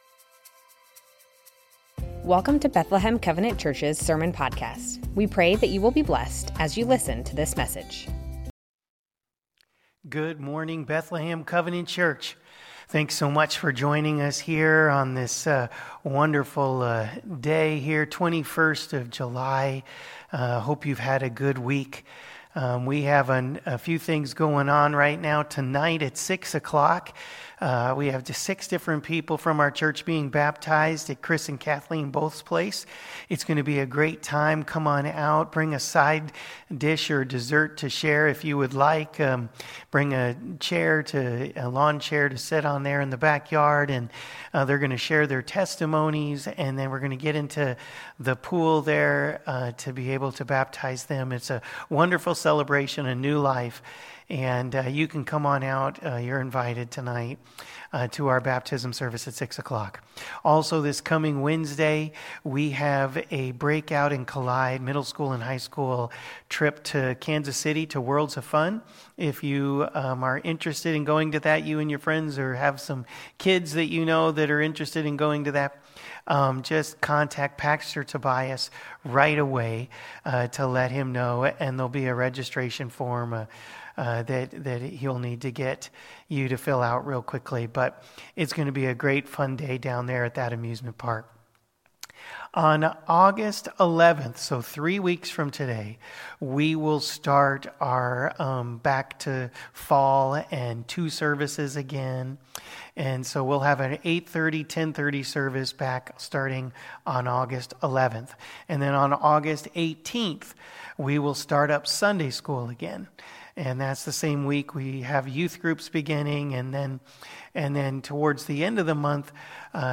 Bethlehem Covenant Church Sermons God is our refuge and strength Jul 21 2024 | 00:35:03 1x 00:00 / 00:35:03 Subscribe Share Spotify RSS Feed Share Link Embed